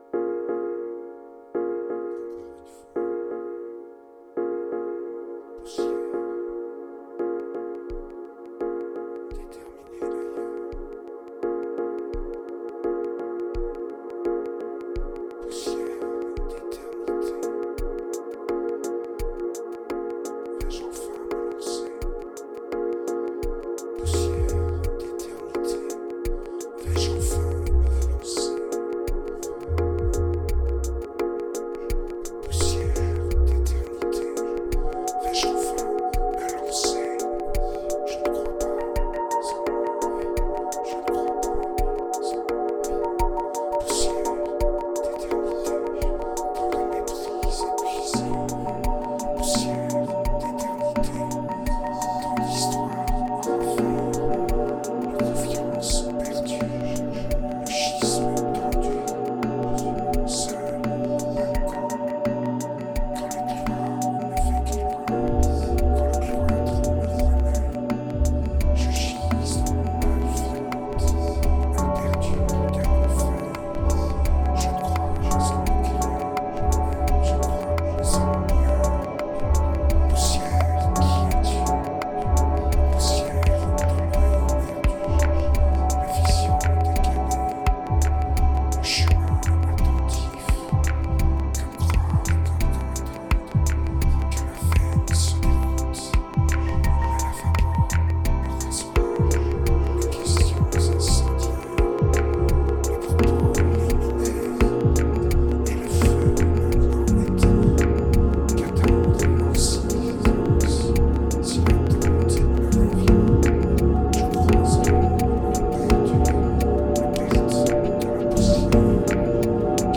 2175📈 - 73%🤔 - 85BPM🔊 - 2015-06-10📅 - 330🌟